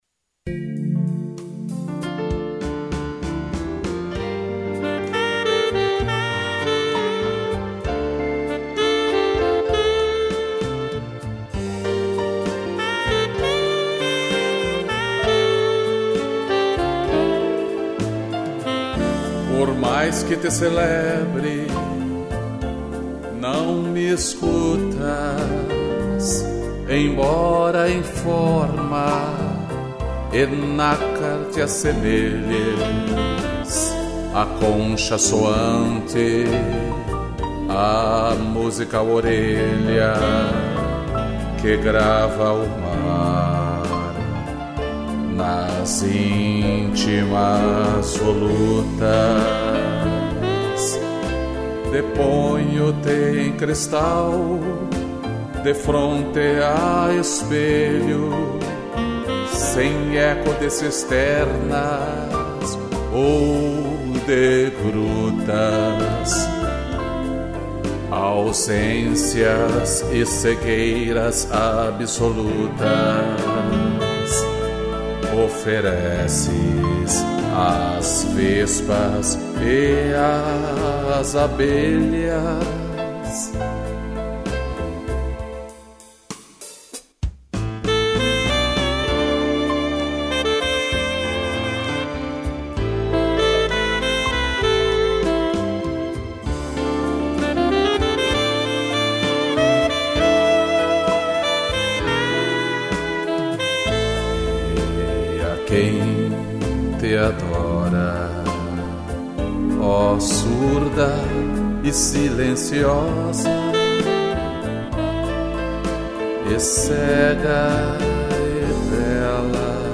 piano, sax e string